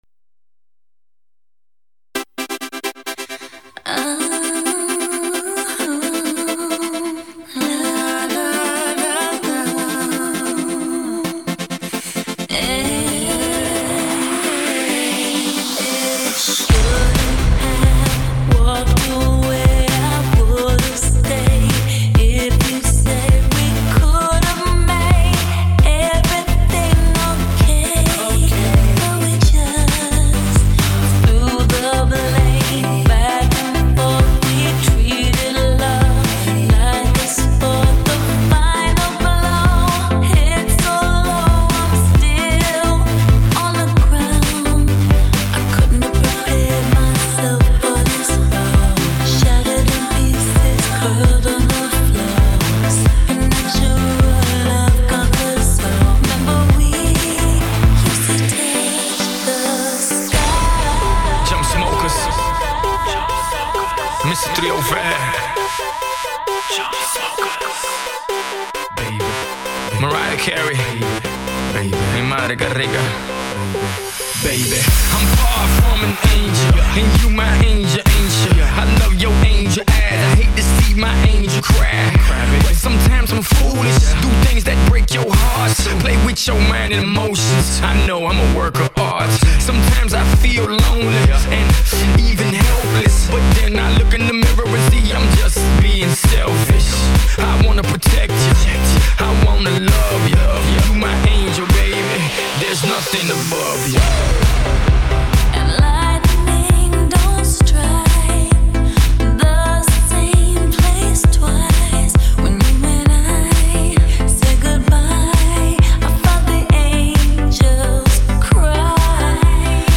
2周前 欧美音乐 11